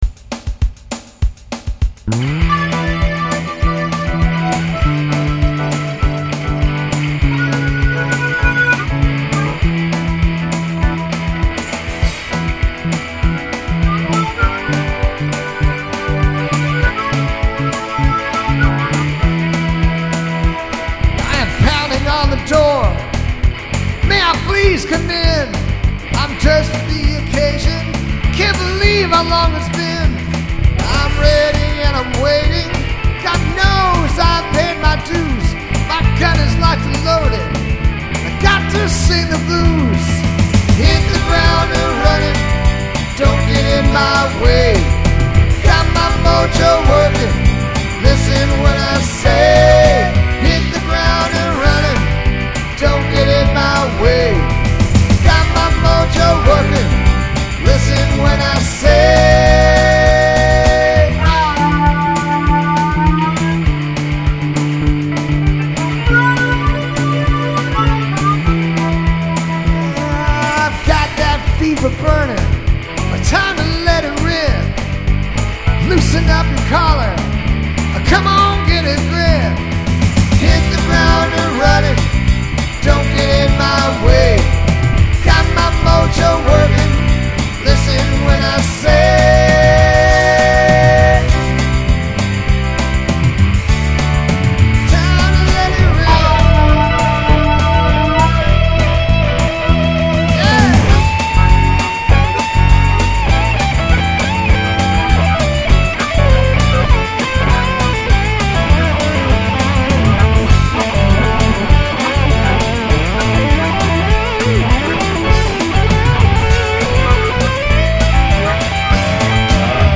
200 b/m 9/6/20